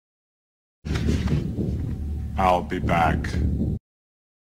I'll Be Back Arnold Schwarzenegger Sound